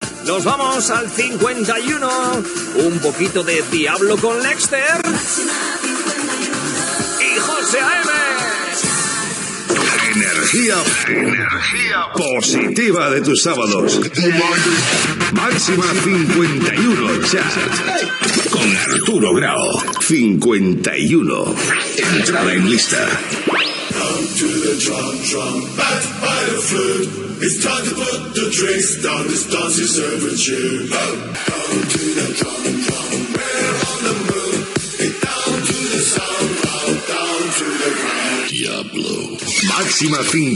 Presentació del tema que entra al número 51 de la llista, indicatiu, tema musical, indicatiu
Musical